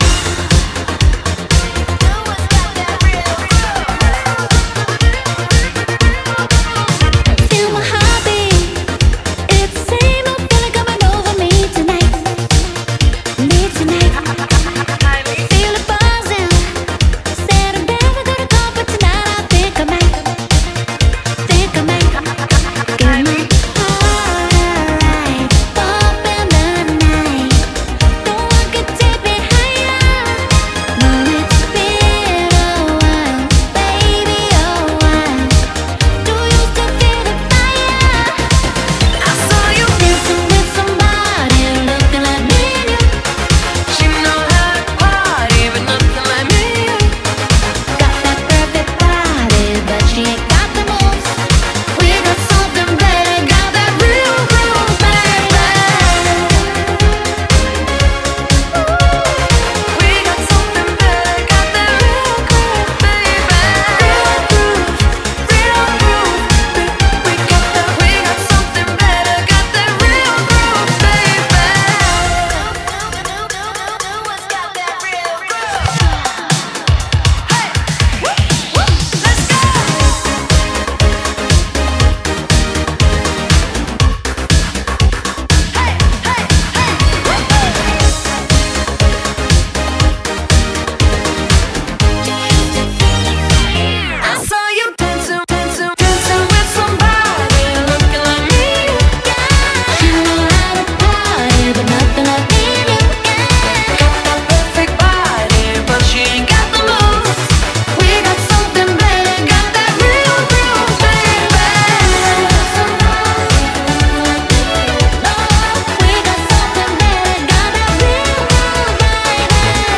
BPM120
Comments[80s STYLE POP]